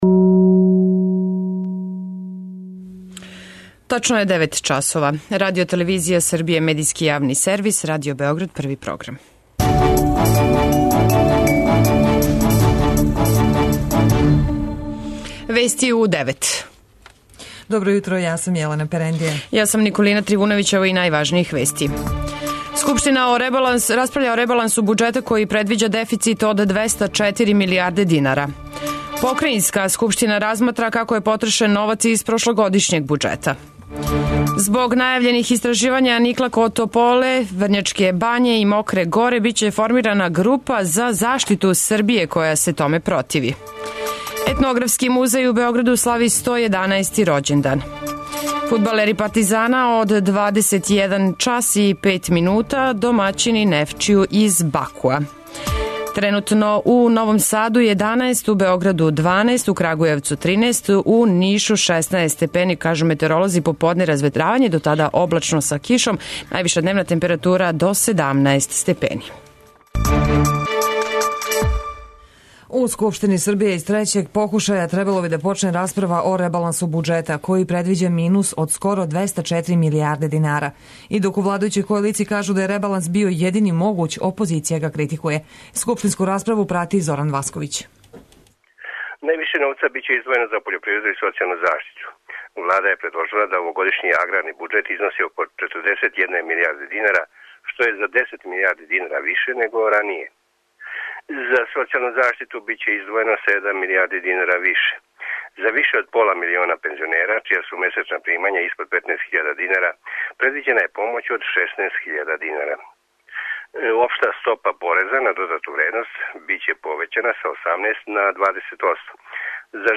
преузми : 9.71 MB Вести у 9 Autor: разни аутори Преглед најважнијиx информација из земље из света.